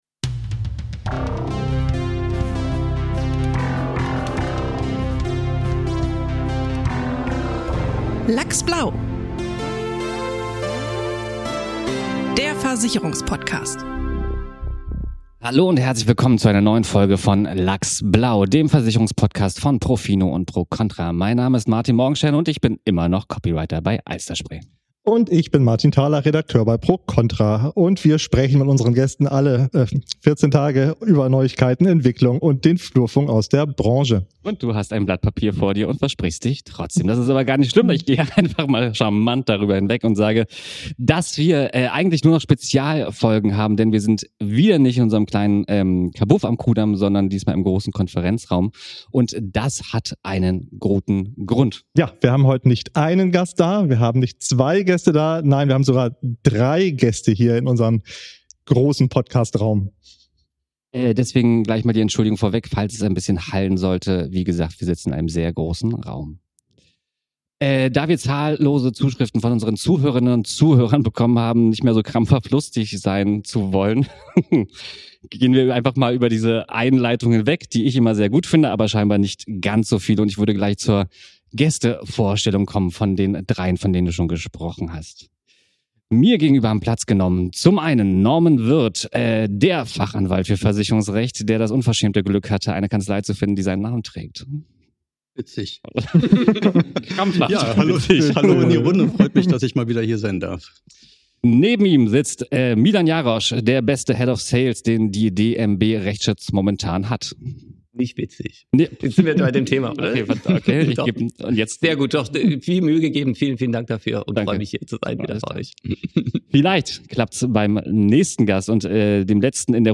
Beschreibung vor 5 Monaten Was passiert, wenn Produktgeber, Fachanwalt und Makler an einem Tisch sitzen?